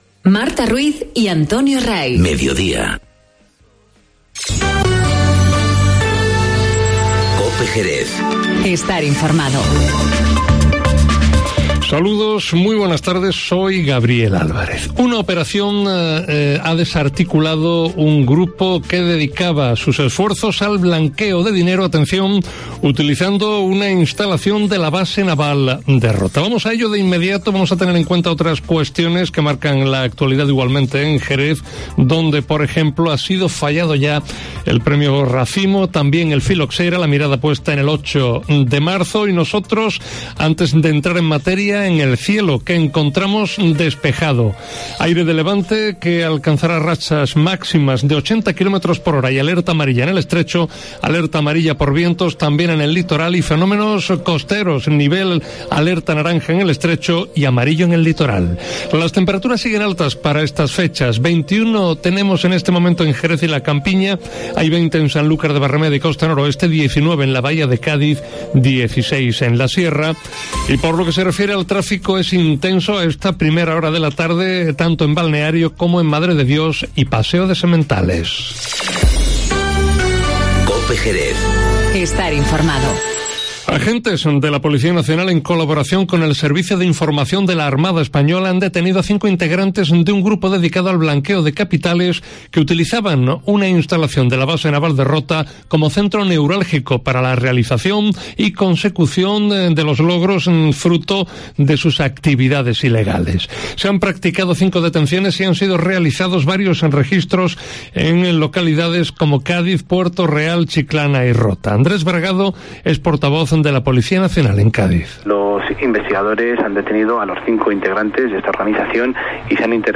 Informativo Mediodía COPE Jerez